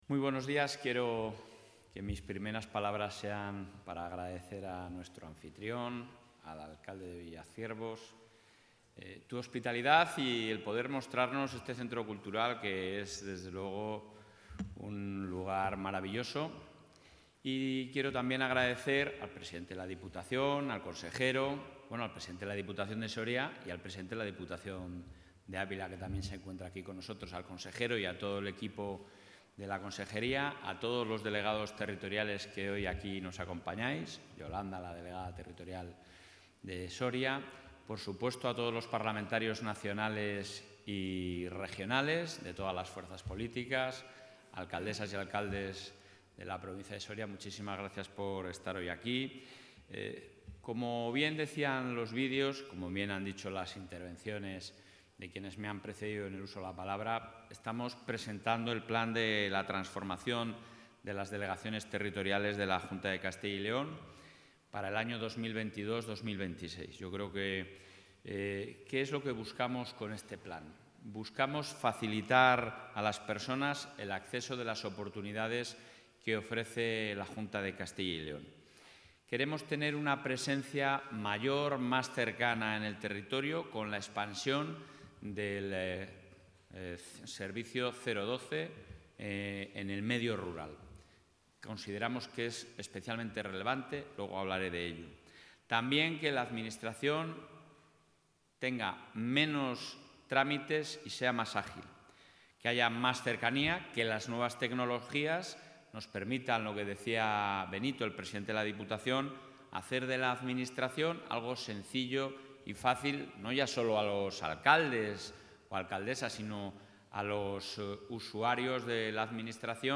En un acto institucional celebrado esta mañana en el municipio soriano de Villaciervos, el presidente de la Junta de Castilla y León,...
Intervención del presidente de la Junta.